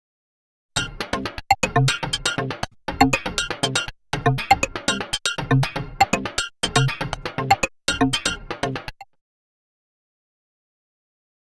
Index of /90_sSampleCDs/Inspiration_Zone/rhythmic loops
05_wavesequence_10_OS.wav